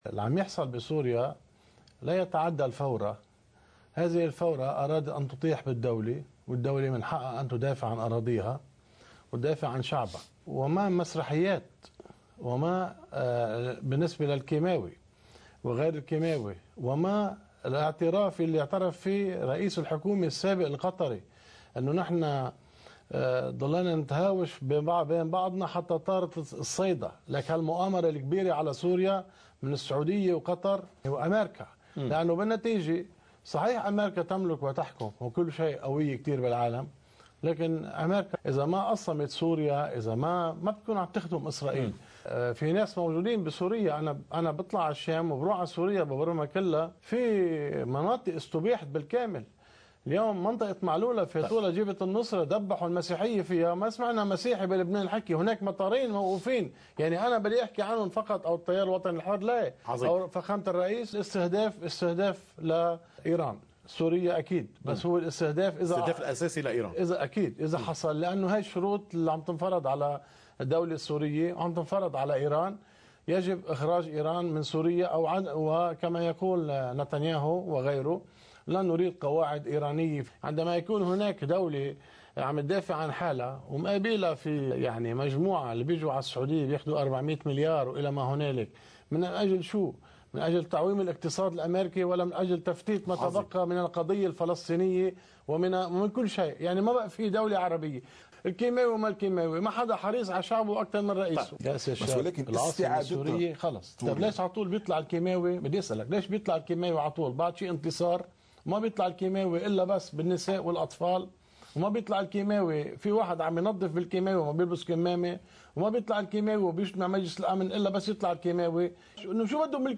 مقتطف من حديث